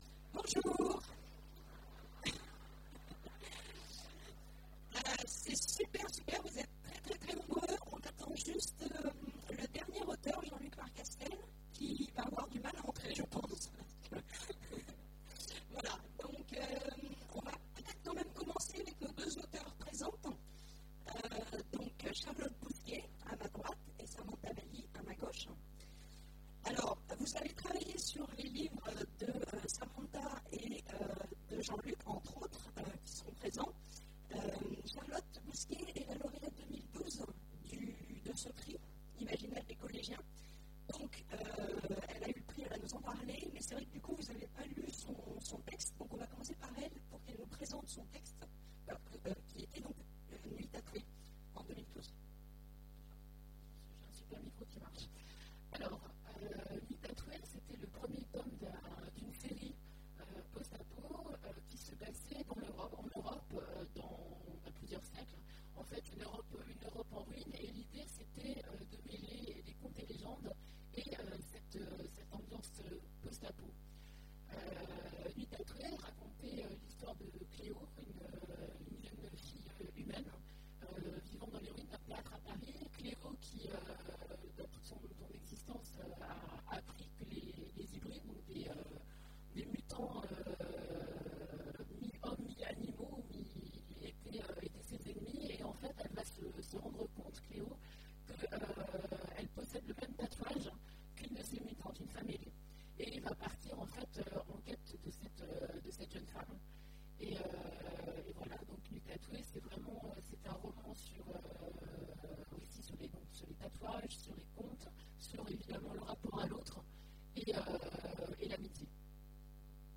Imaginales 2015 : Conférence Prix Imaginales des collégiens
Conférence
Mots-clés Rencontre avec un auteur Conférence Partager cet article